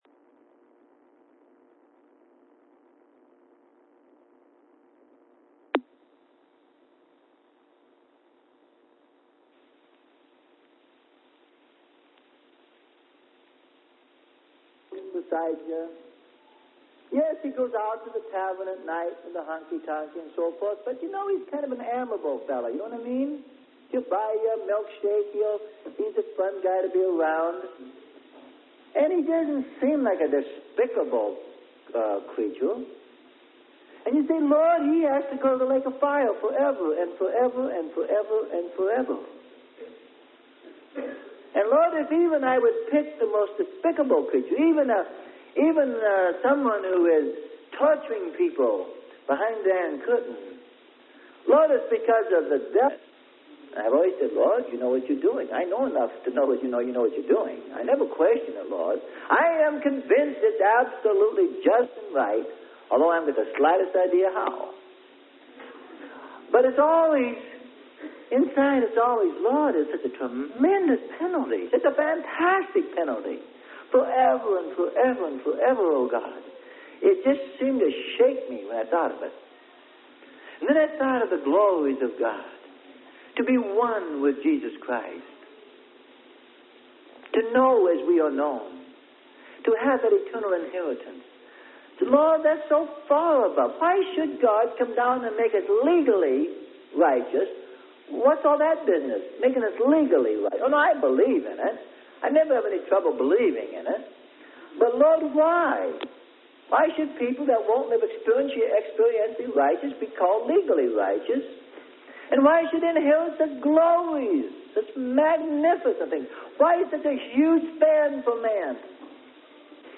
Sermon: Love The Marrying Kind: Proper Premise For The Bride.